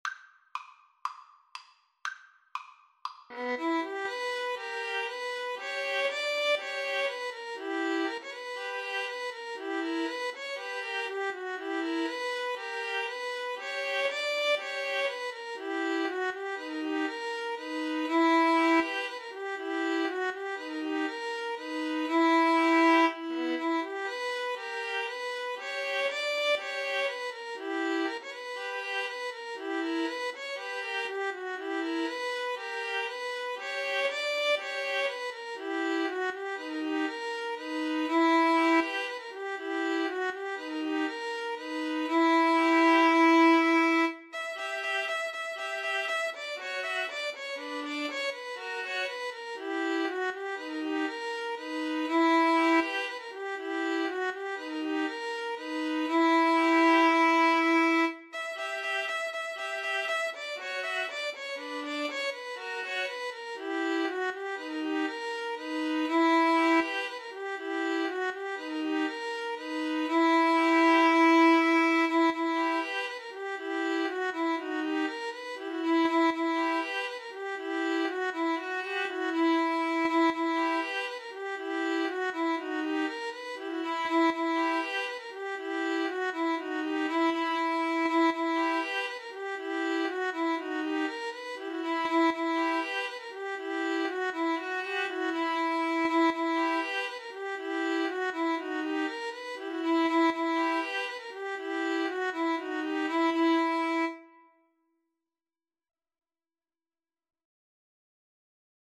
D major (Sounding Pitch) (View more D major Music for String trio )